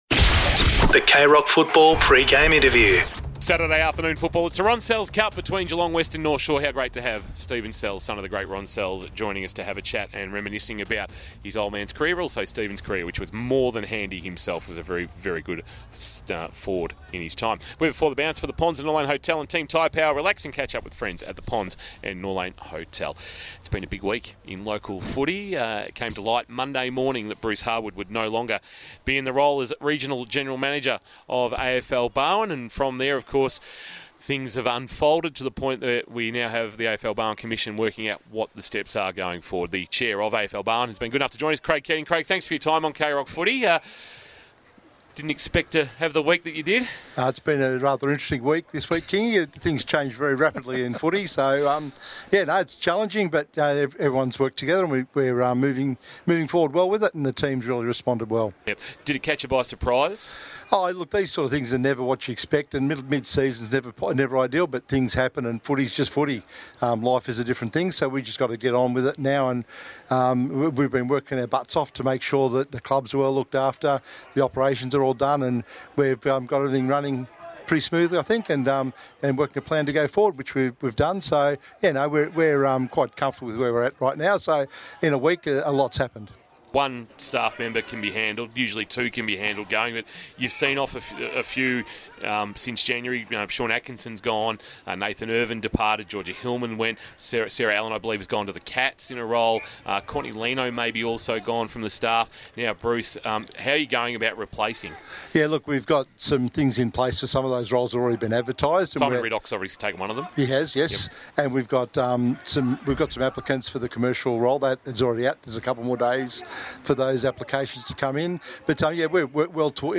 2022 – GFL ROUND 9 – GEELONG WEST vs. NORTH SHORE: Pre-match Interview